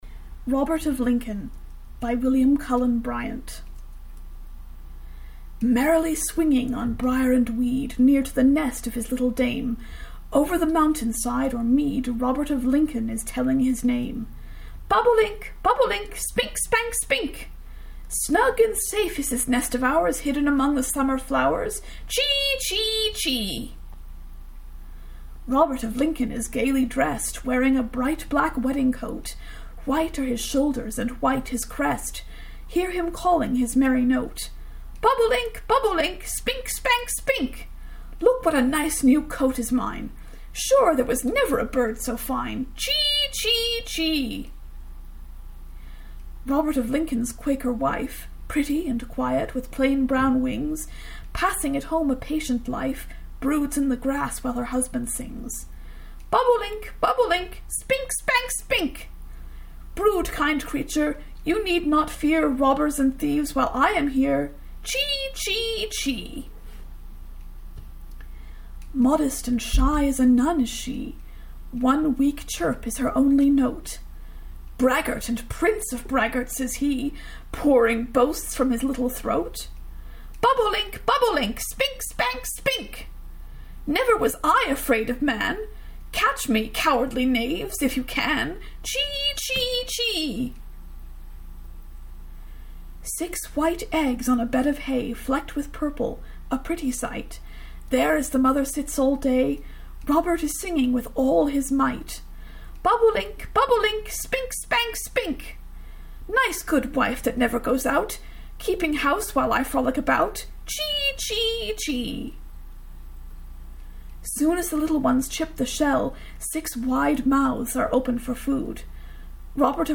This one is such fun to read out loud!